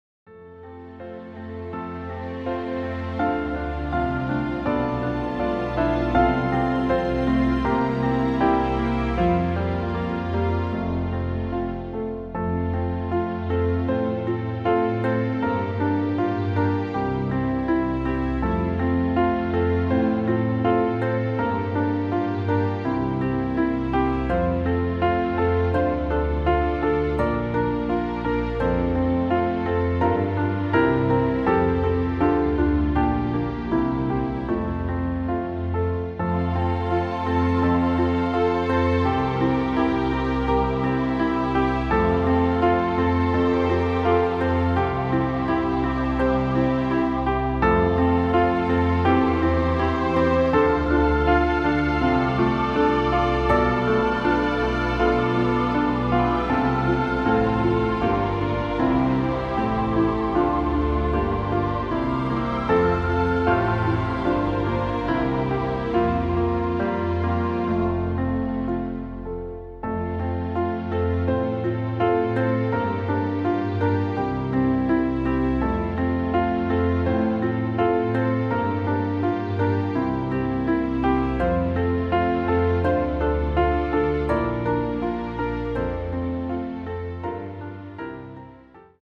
F Dur Klavierversion
• Tonart: C, D, Eb, F
• Das Instrumental beinhaltet NICHT die Leadstimme
Klavier / Streicher